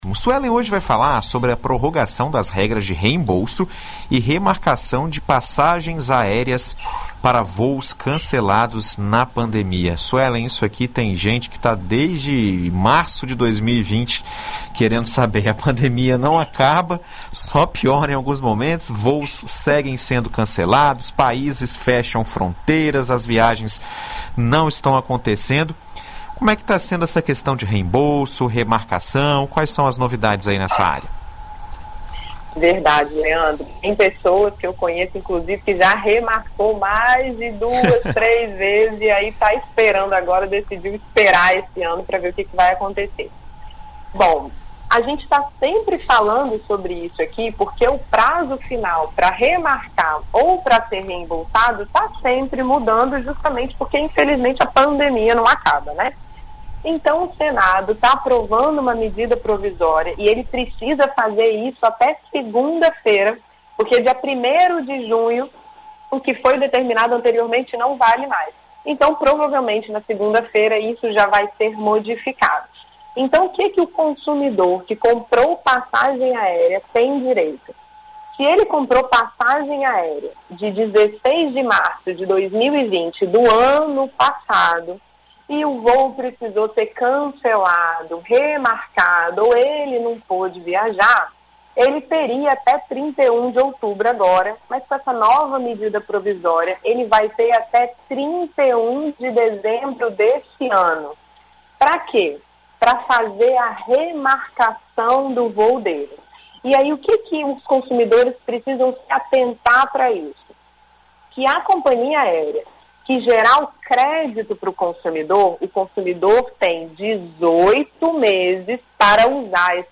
Na BandNews FM, advogada explica novos prazos e destaca alguns cuidados que o consumidor deve tomar para garantir o seu direito nas passagens aéreas canceladas